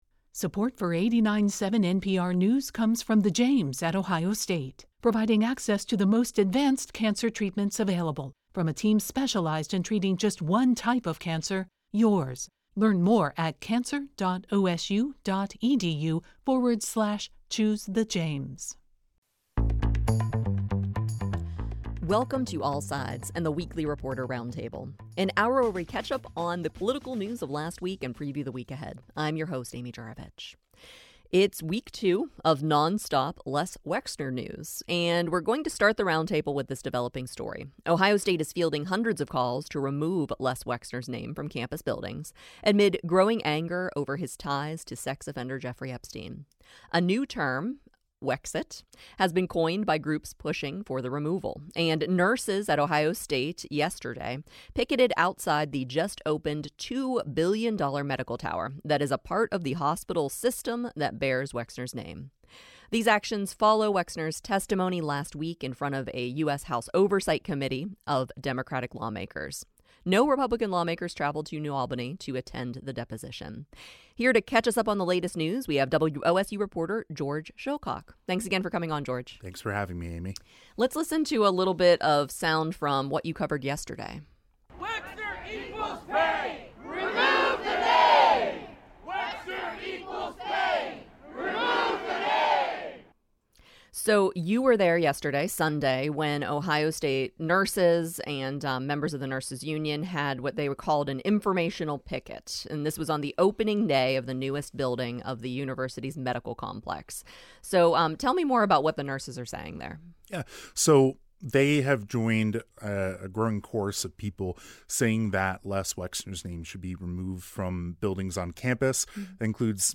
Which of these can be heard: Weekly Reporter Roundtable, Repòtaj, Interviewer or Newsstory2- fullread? Weekly Reporter Roundtable